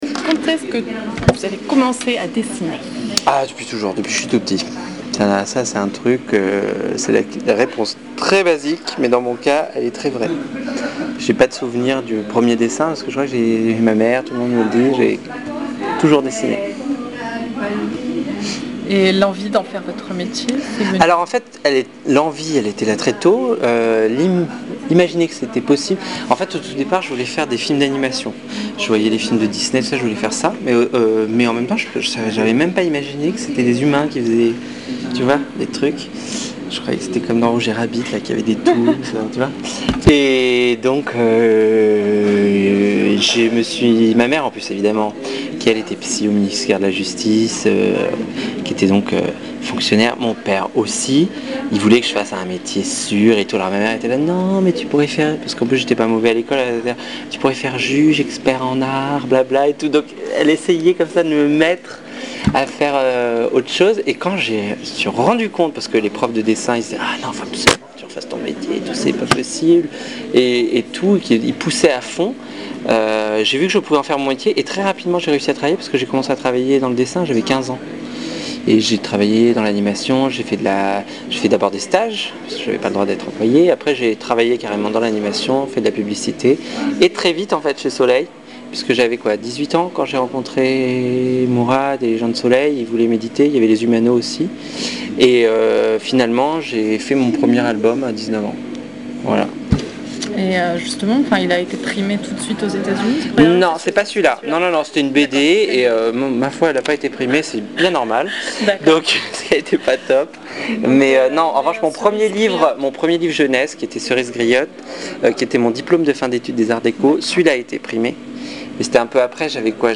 Interview Benjamin Lacombe